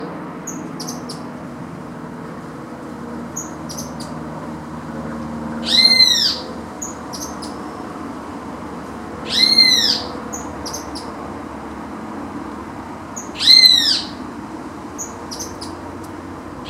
White-crested Tyrannulet (Serpophaga subcristata)
Piojito común y benteveo
Life Stage: Adult
Condition: Wild
Certainty: Recorded vocal